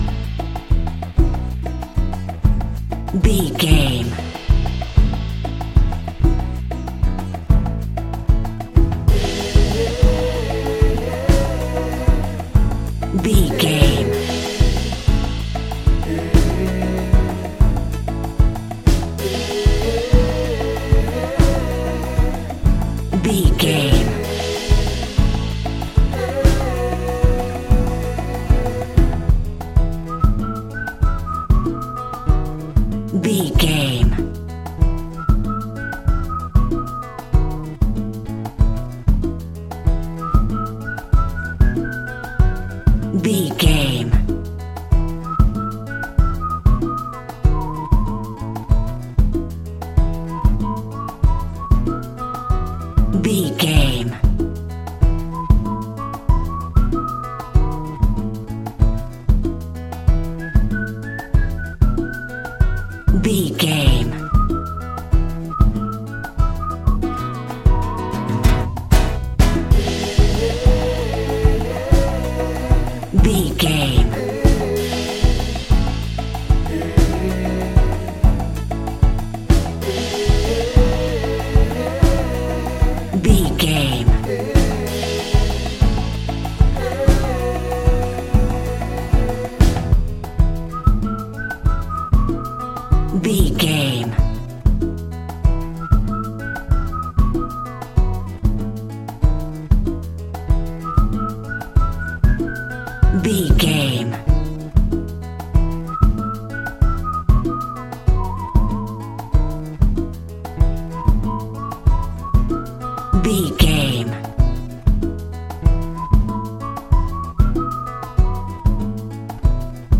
Uplifting
Ionian/Major
flamenco
maracas
percussion spanish guitar
latin guitar